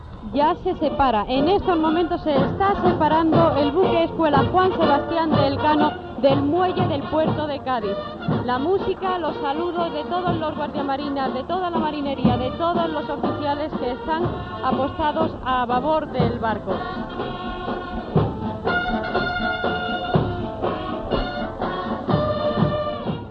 Testimoni de la mare d'un dels mariners.
Informatiu